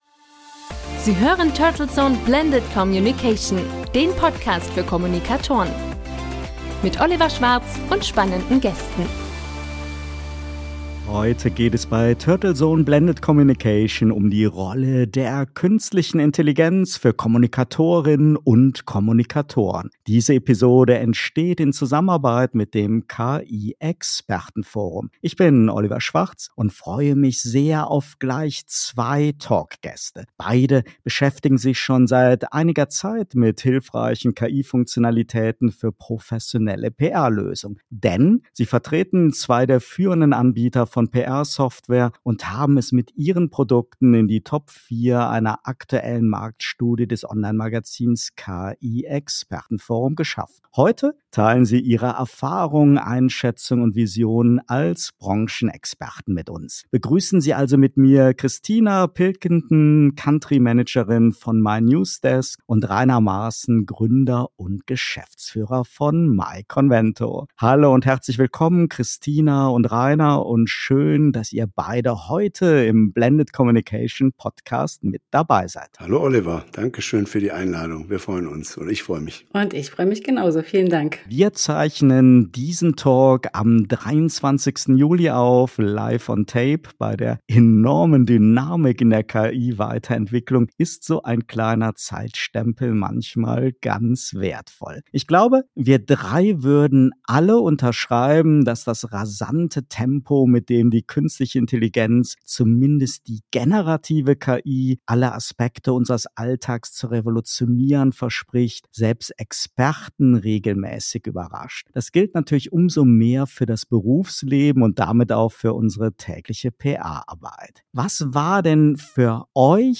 Und natürlich der jeweilige Interviewgast.„Blended Communication“ bedeutet die nahtlose Integration von traditioneller Presse- & Öffentlichkeitsarbeit sowie interner Kommunikation mit digitalen, internetbasierten Werkzeugen und Channels.